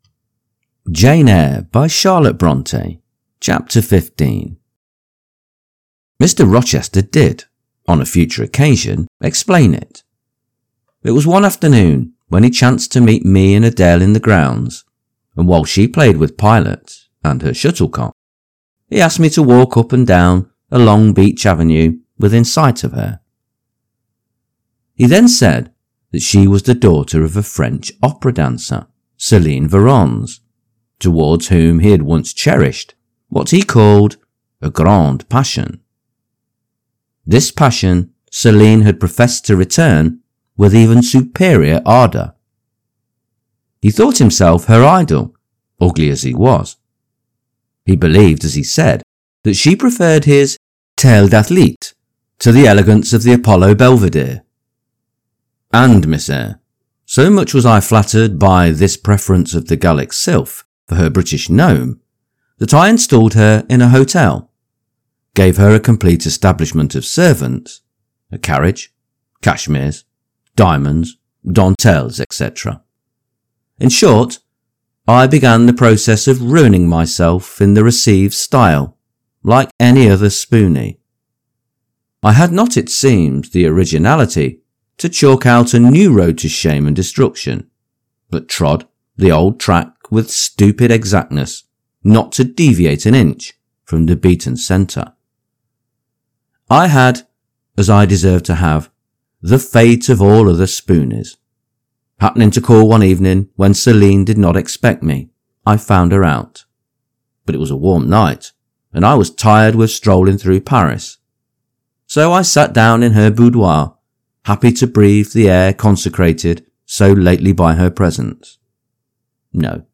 Jane Eyre – Charlotte Bronte – Chapter 15 | Narrated in English